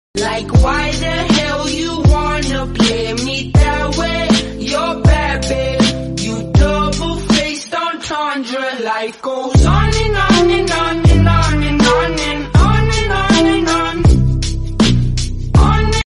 Duck song, walking, happy, rainbow sound effects free download
Duck song, walking, happy, rainbow background, duck